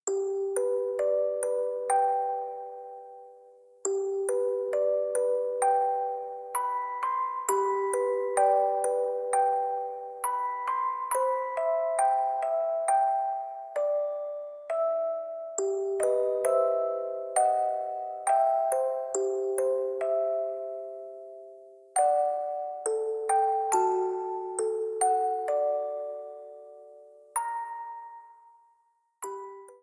A blue streamer theme
Ripped from the game
clipped to 30 seconds and applied fade-out